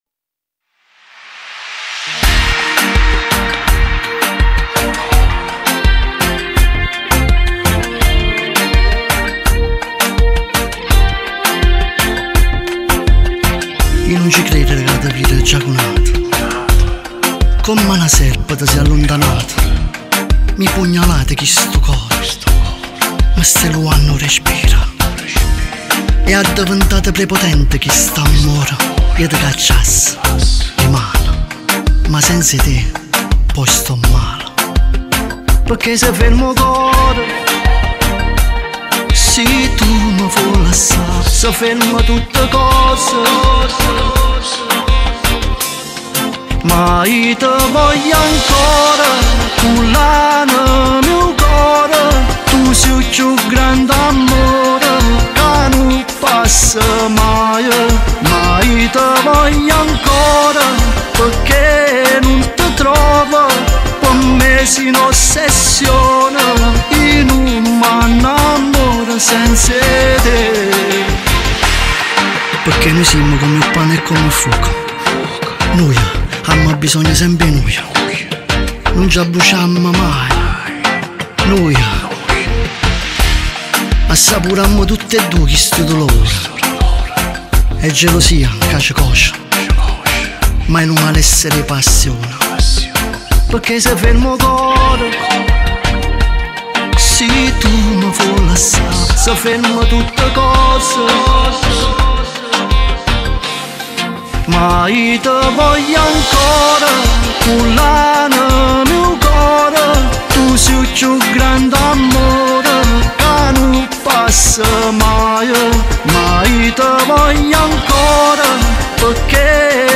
Con la sua voce intensa e testi evocativi